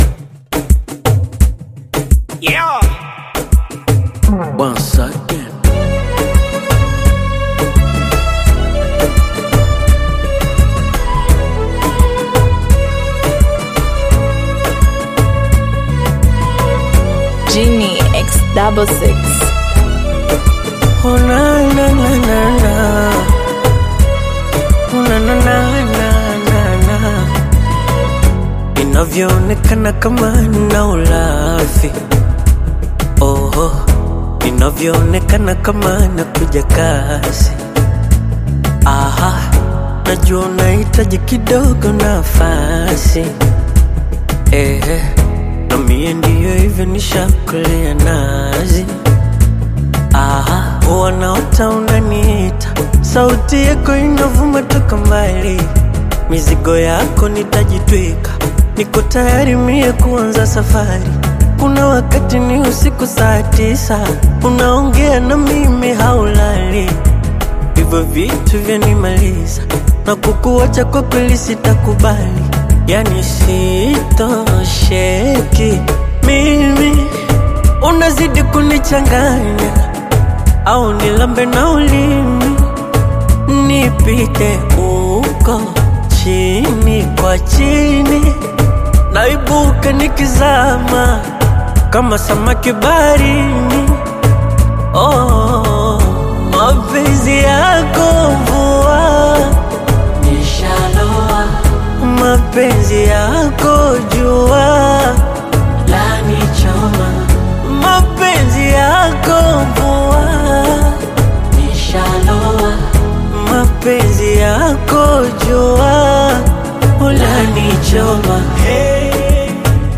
Tanzanian singer